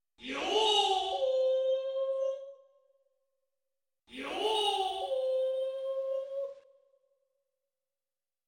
Roland's Virtual Sound Canvas is essentially gm.dls, but with newly added samples of SC-88 and 88Pro instruments. Among the newly added samples is a sample of the Yyoo Dude patch from the SC-88Pro Asia kit.
Interestingly, Roland seems to have used the original Yyoo Dude sample when adding the patch to Virtual Sound Canvas, rather than using the SC-88Pro version of the sample, which is slightly shorter.